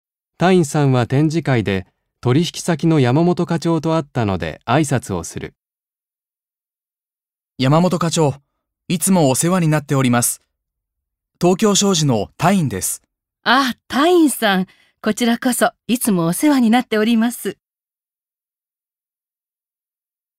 1.1. 会話（社外の人との挨拶あいさつ